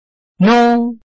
臺灣客語拼音學習網-客語聽讀拼-饒平腔-鼻尾韻
拼音查詢：【饒平腔】nung ~請點選不同聲調拼音聽聽看!(例字漢字部分屬參考性質)